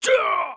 damage_4.wav